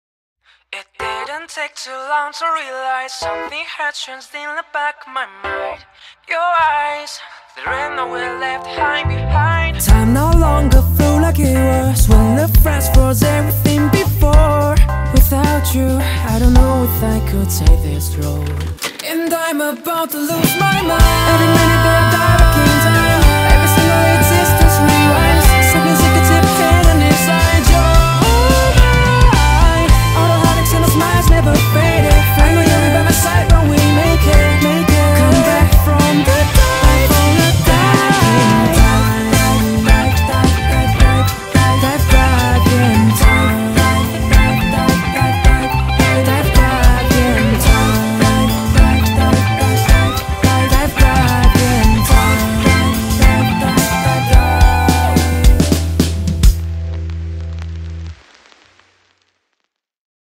BPM108
OP theme